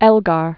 (ĕlgär, -gər), Sir Edward 1857-1934.